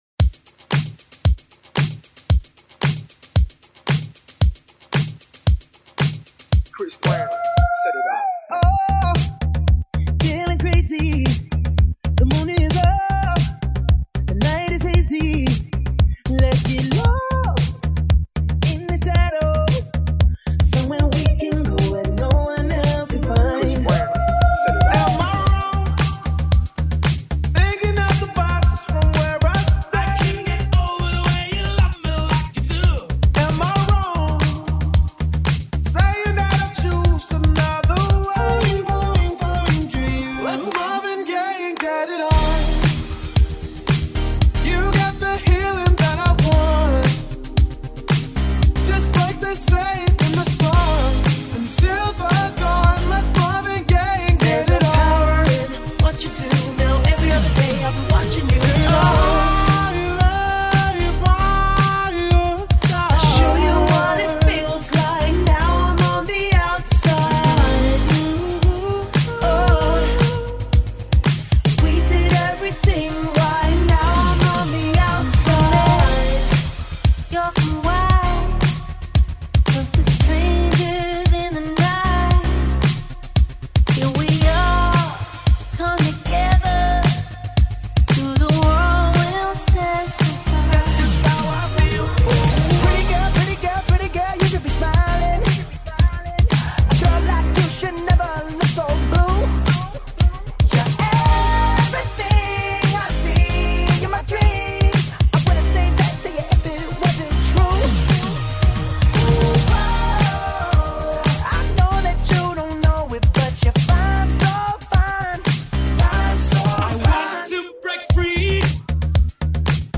Excelente multimashup